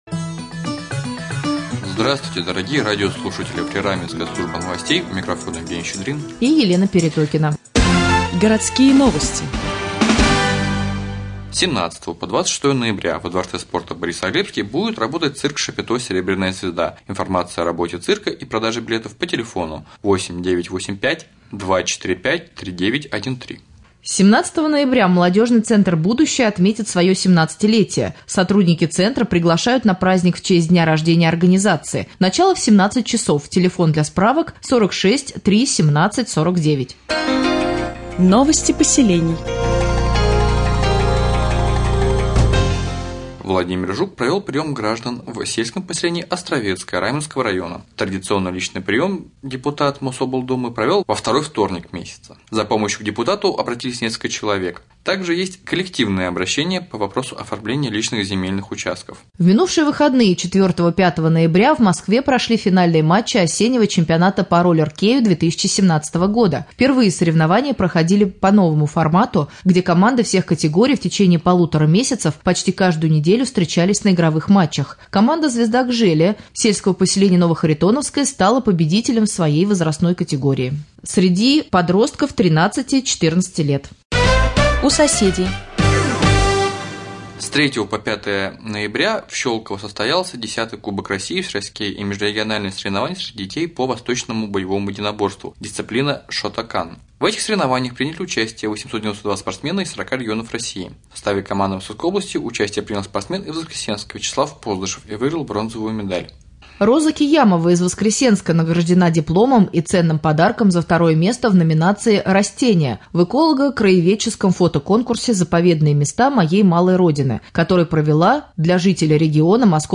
Сегодня в новостном выпуске на Раменском радио Вы узнаете, когда во дворец спорта «Борисоглебский» приедет цирк шапито, как молодежный центр «Будущее» отметит свое 17-летие , а также последние областные новости и новости соседних районов.